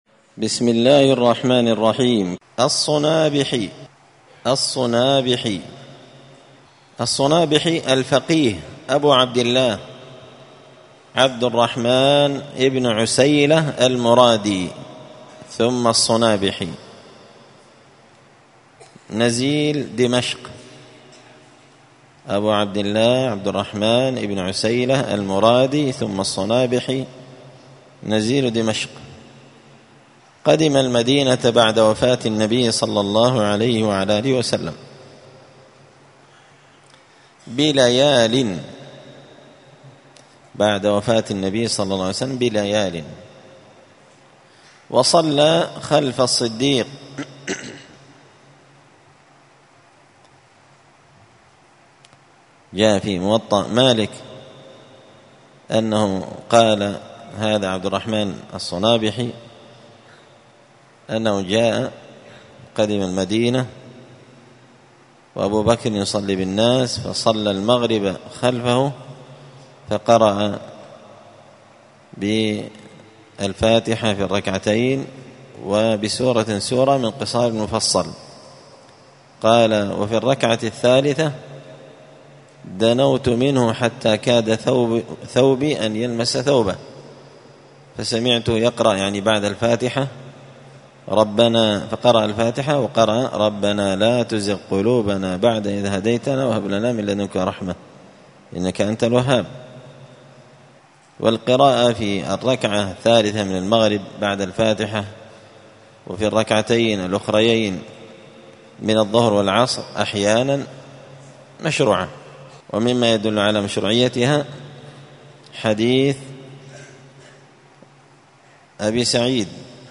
قراءة تراجم من تهذيب سير أعلام النبلاء